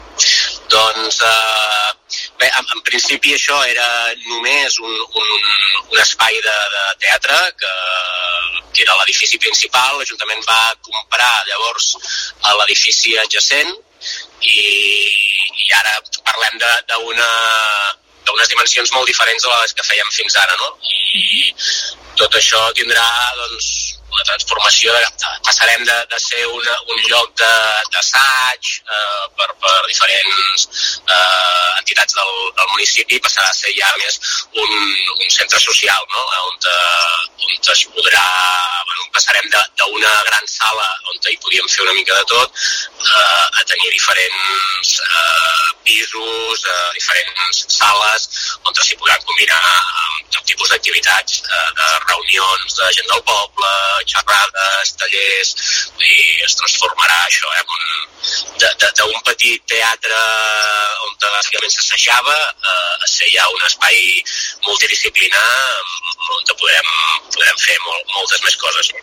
L’alcalde del municipi, Daniel Serrano Torró, afirma que l’antic Cafè-Teatre de Rupià és un edifici amb molta història. La intenció és que la reforma serveixi per convertir ambdós edificis en una mena de centre social perquè, a part d’acollir teatre, s’hi puguin dur a terme diverses activitats socials amb les diferents entitats municipals.